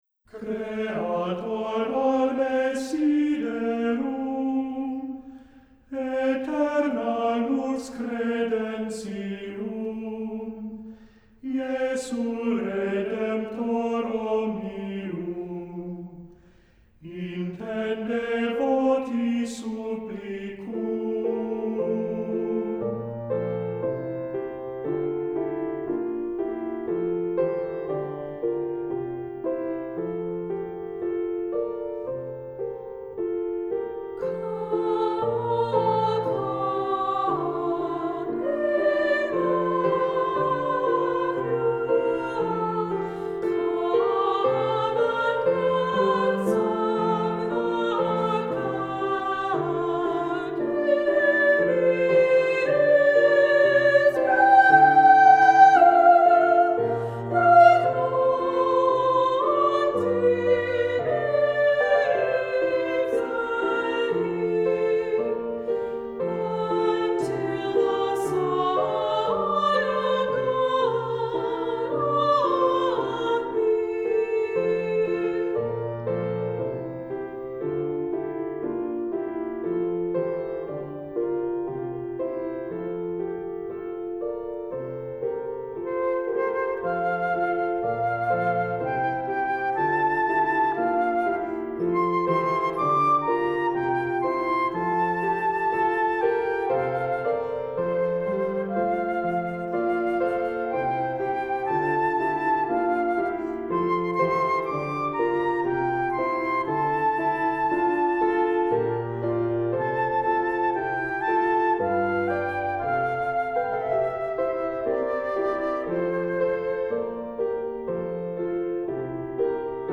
Accompaniment:      Keyboard, C Instrument
Music Category:      Choral